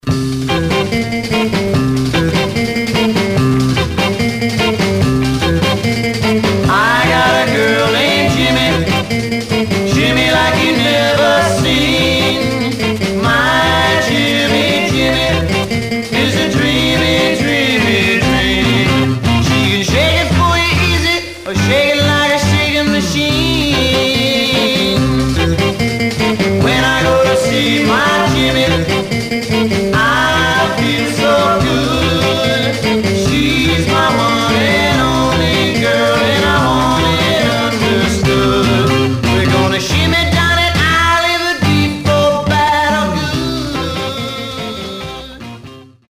Much surface noise/wear
Mono
Garage, 60's Punk